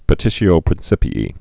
(pə-tĭshē-ō prĭn-sĭpē-ē, -ē-ī)